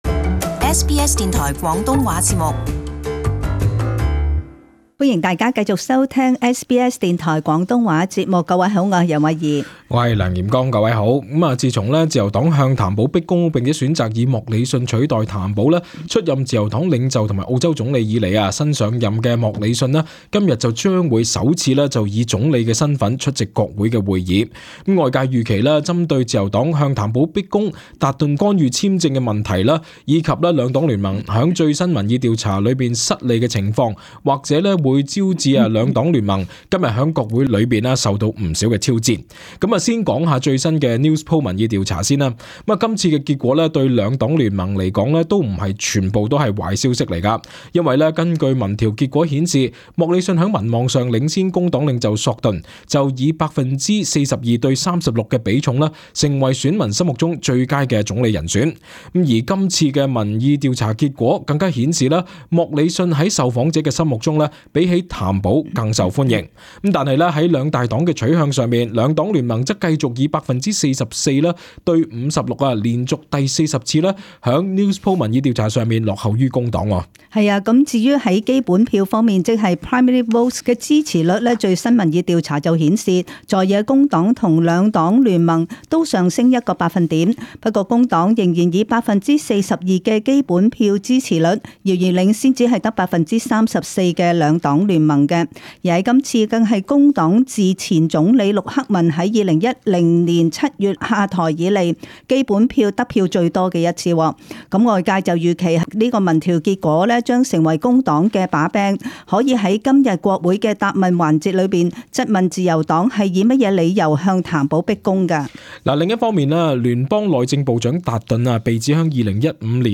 【時事報導】國會復會新任總理將面臨重重困難